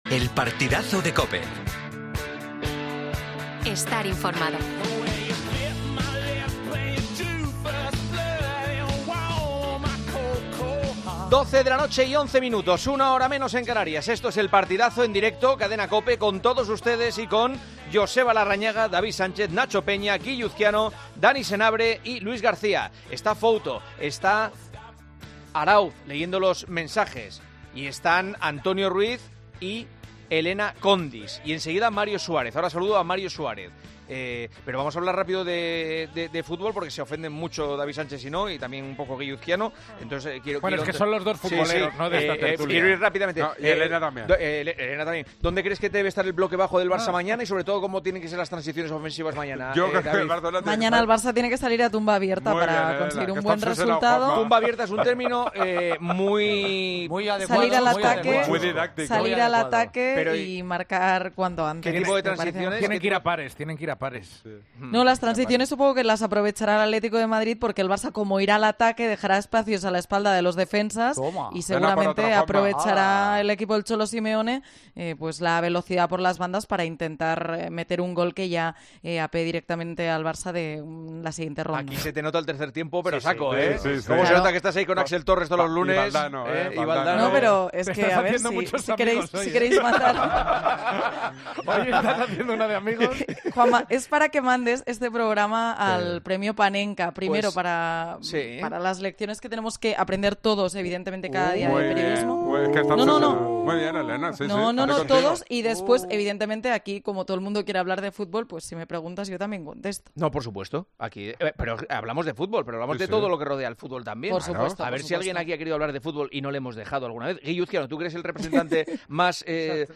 El análisis sobre las posibilidades del FC Barcelona para remontar en la Champions League ha generado un intenso debate en El Partidazo de COPE.